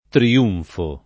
tri-2nfo]: L’alto triunfo del regno verace [